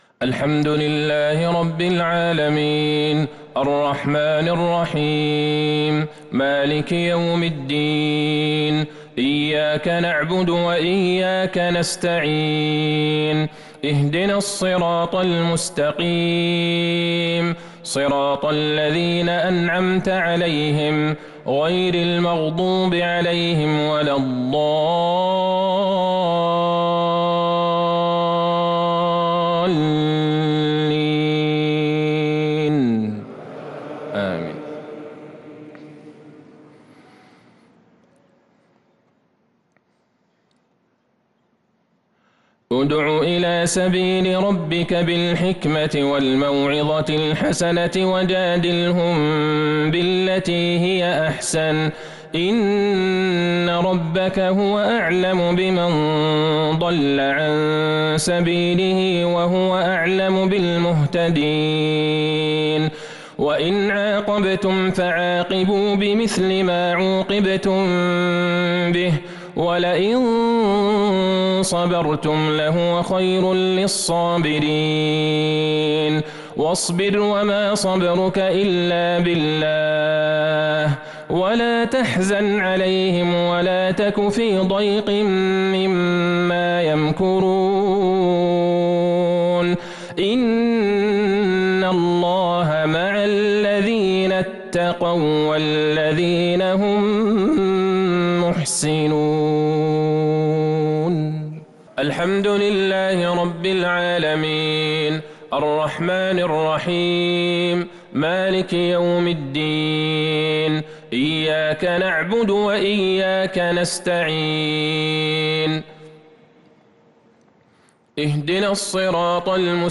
صلاة العشاء للقارئ عبدالله البعيجان 29 شعبان 1443 هـ